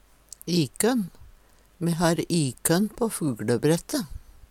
ikønn - Numedalsmål (en-US)